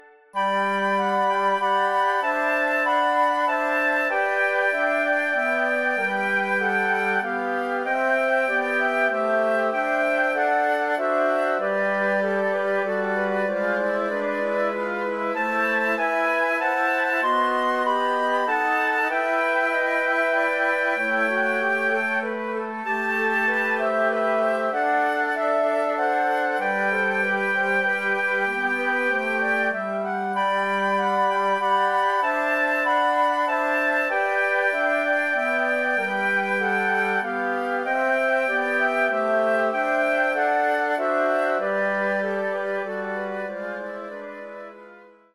Besetzung: Flötenquintett
3 meditative Adventslieder für Flötenquartett